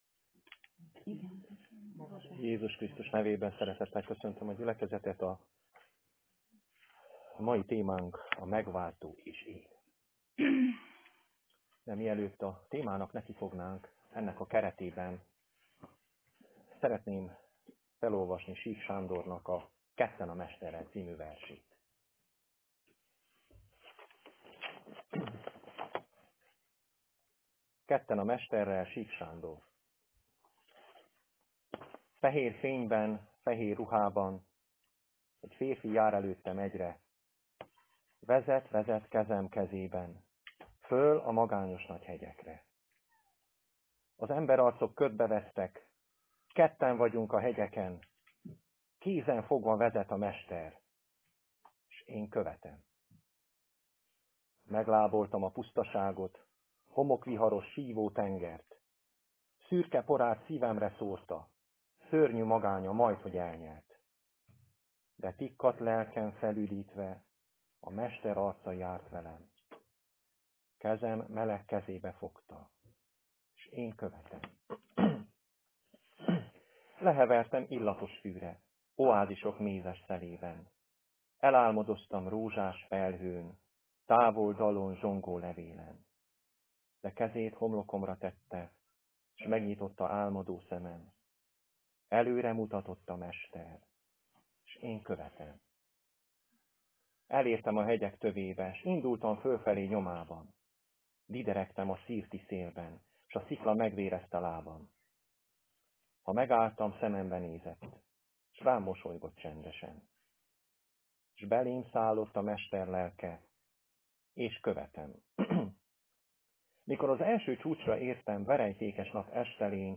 A Megváltó és én Igehirdetések mp3 Link az igehirdetéshez Hasonló bejegyzések Igehirdetések mp3 Bűnrendezésről.